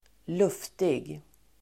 Ladda ner uttalet
luftig.mp3